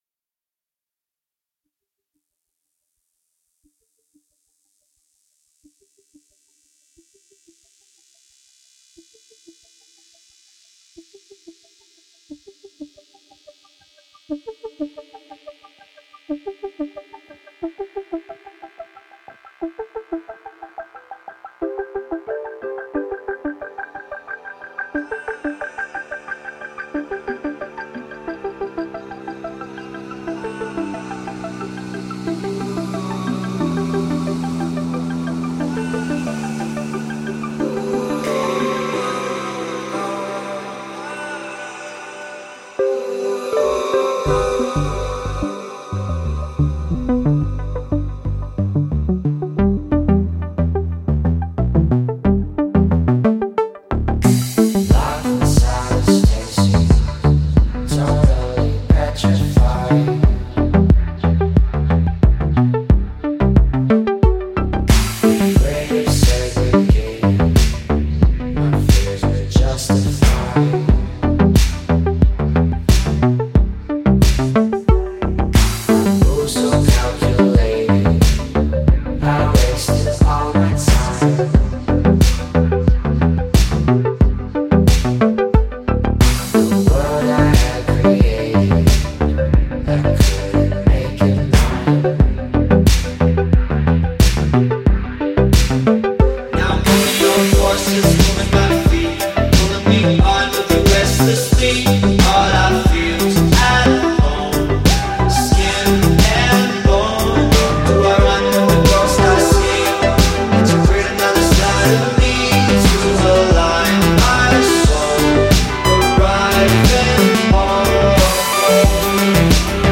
electropop